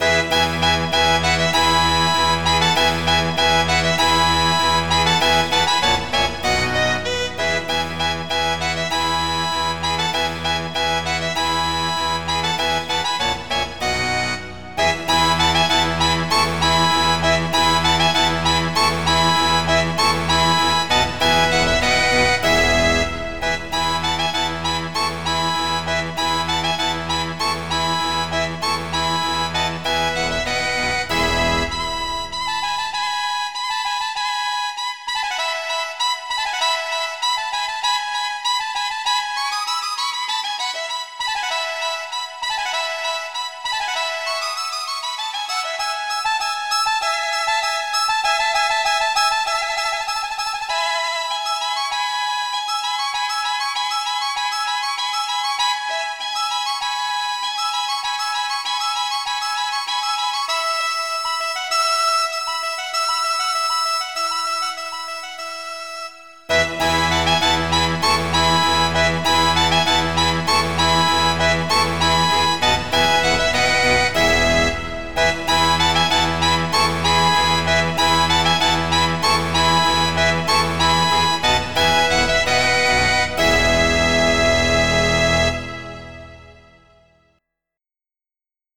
MIDI Music File
Title Four Seasons Type General MIDI